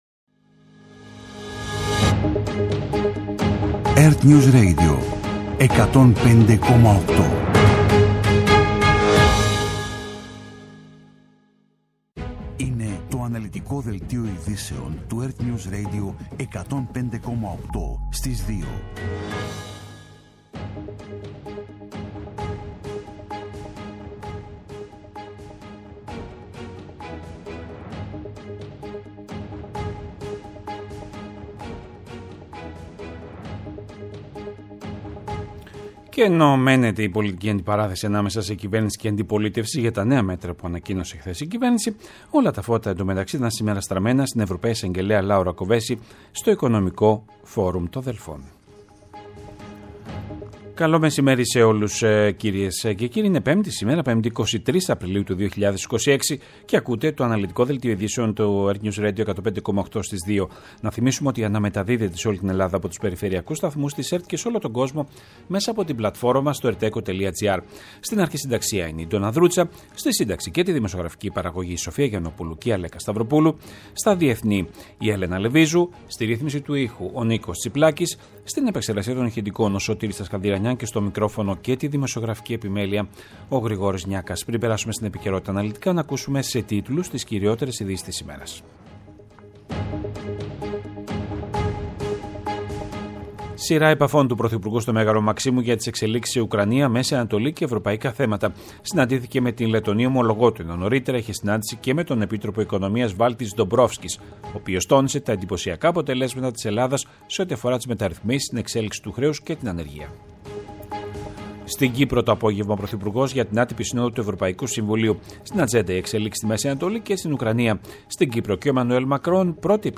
Το αναλυτικό ενημερωτικό μαγκαζίνο στις 14:00.
Με το μεγαλύτερο δίκτυο ανταποκριτών σε όλη τη χώρα, αναλυτικά ρεπορτάζ και συνεντεύξεις επικαιρότητας.